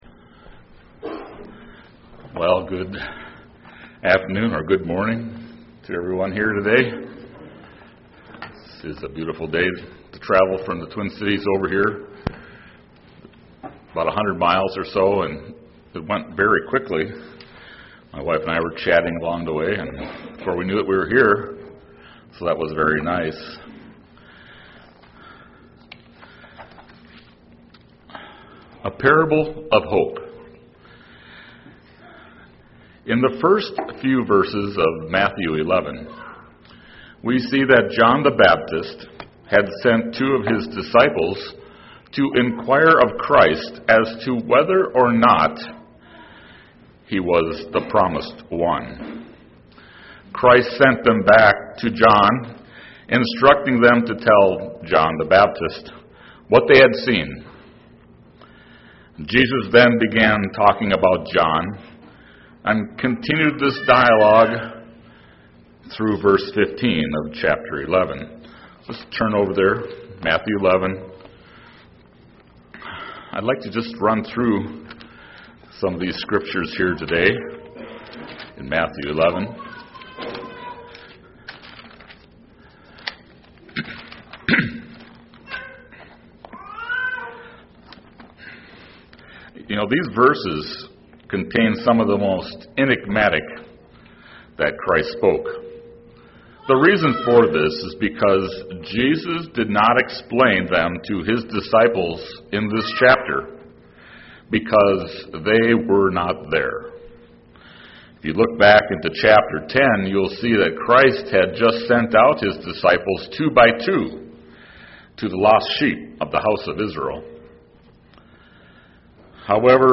UCG Sermon parable Elijah hope Studying the bible?